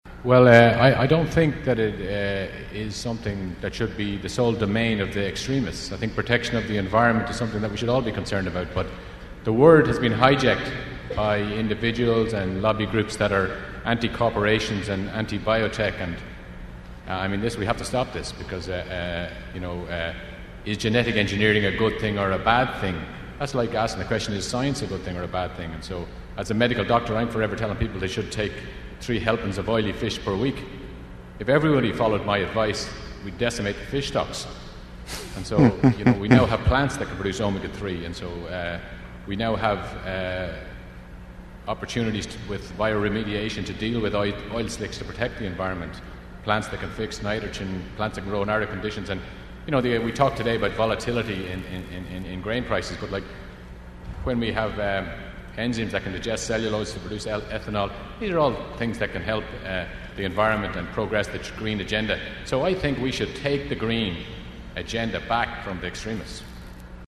Great Debate at Alltech Symposium